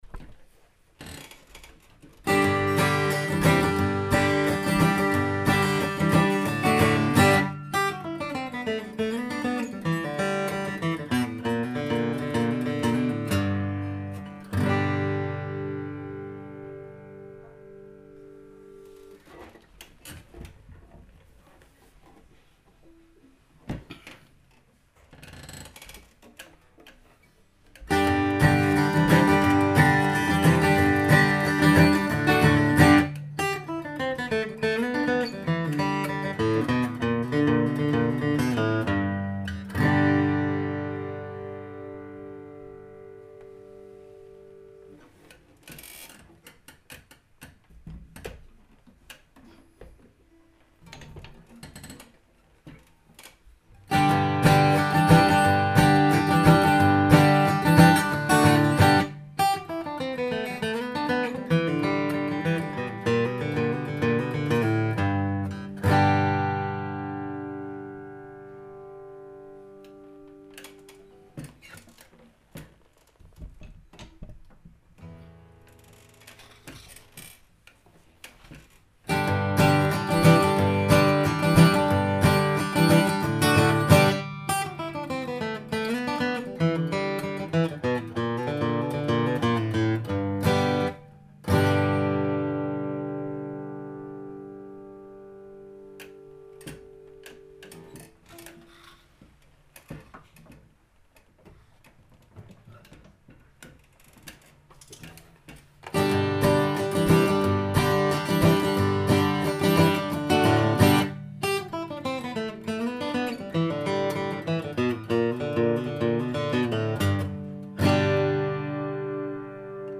Третья запись - это быстрое G-штук на каждой из шести гитар.
G Материал на шести гитарах D28